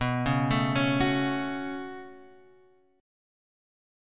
B7sus2 chord